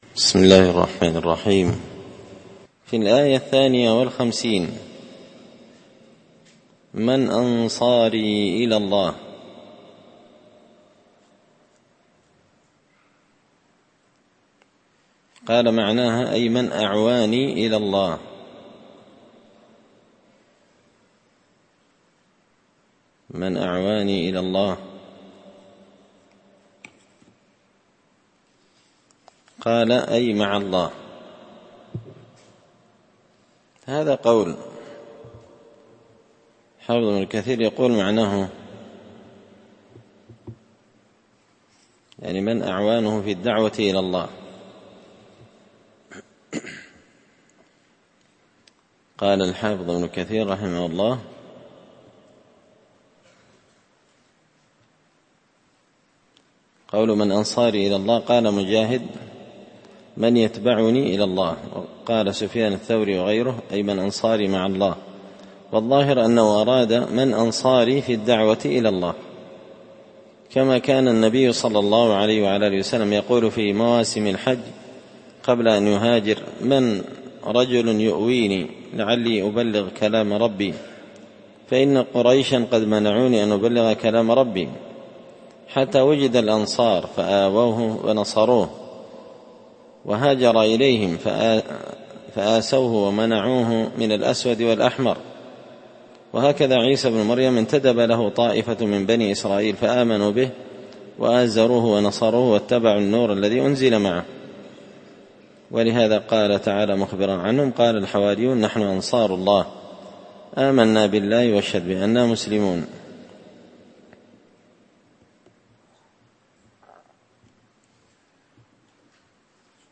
تفسير مشكل غريب القرآن ـ الدرس 63
دار الحديث بمسجد الفرقان ـ قشن ـ المهرة ـ اليمن